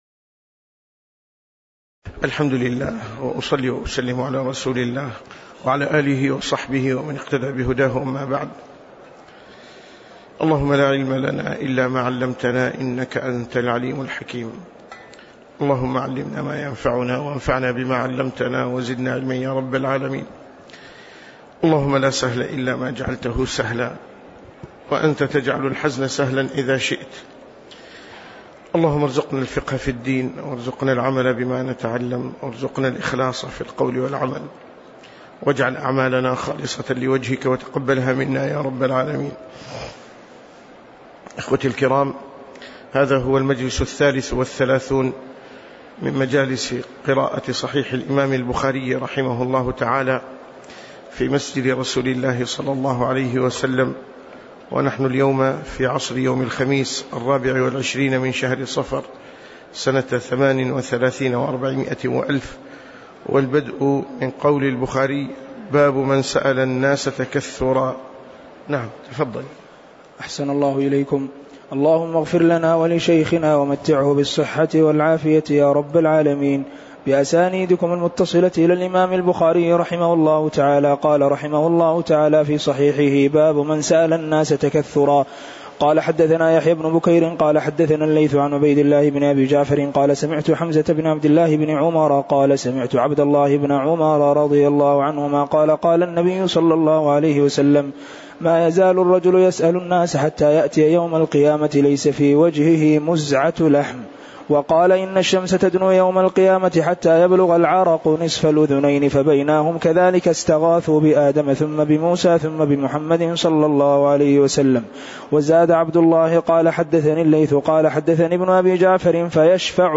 تاريخ النشر ٢٤ صفر ١٤٣٨ هـ المكان: المسجد النبوي الشيخ